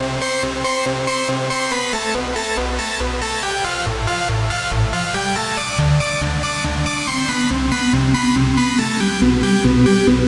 这个版本是大钢琴的声音。
声道立体声